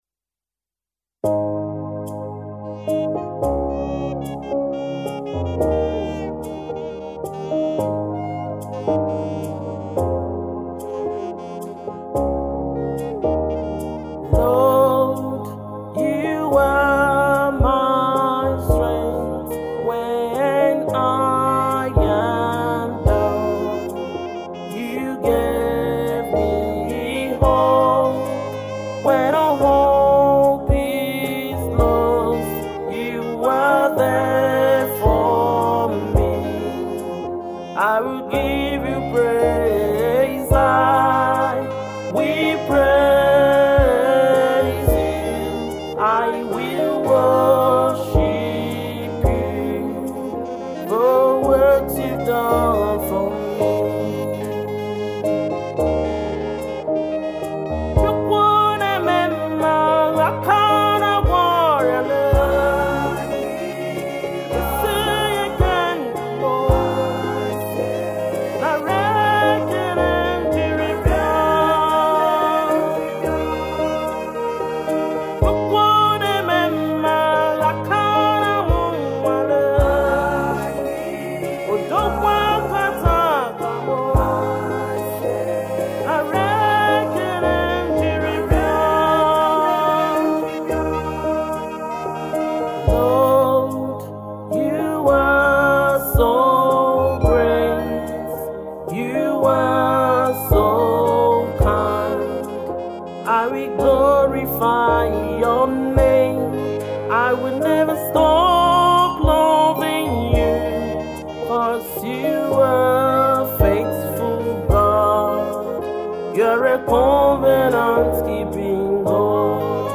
gospel singer and songwriter